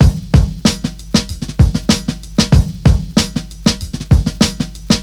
• 95 Bpm Breakbeat Sample E Key.wav
Free drum groove - kick tuned to the E note. Loudest frequency: 1442Hz
95-bpm-breakbeat-sample-e-key-Krs.wav